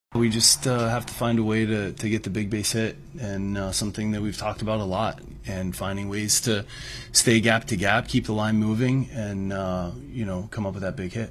Manager Donnie Kelly says the Pirates are not measuring up offensively.